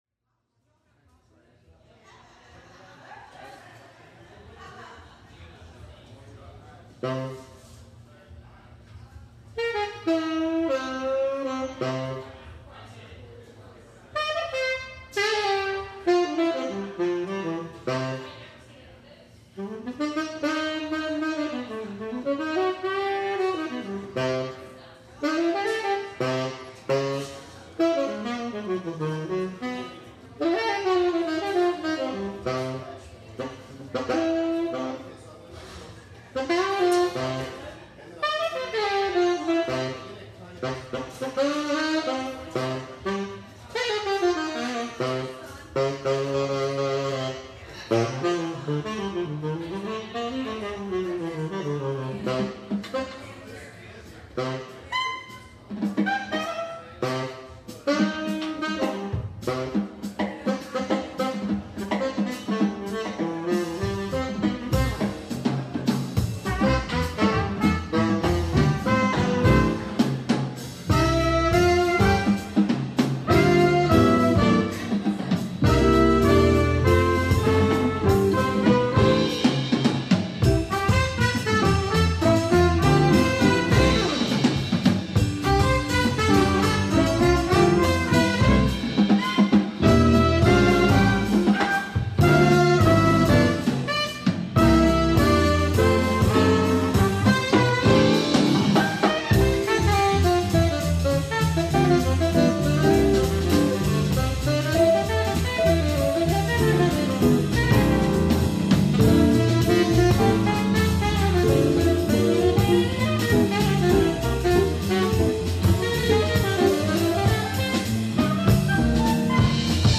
Tenor Sax